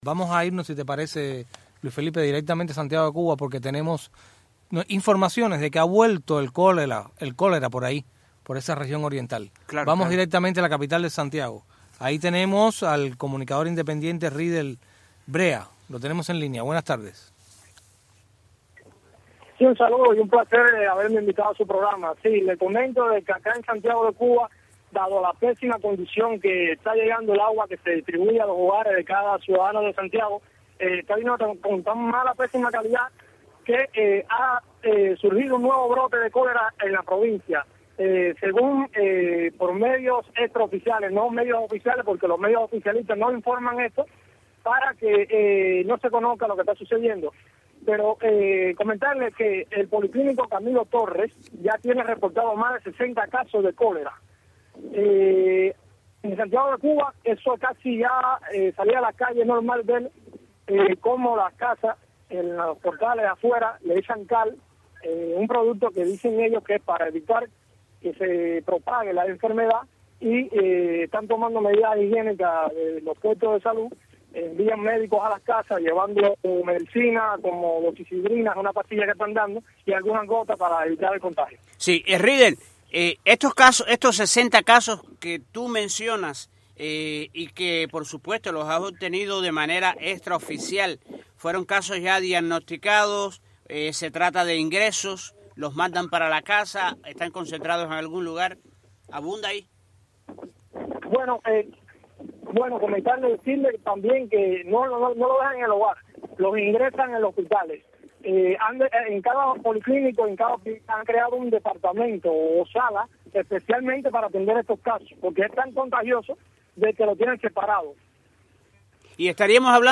Periodista independiente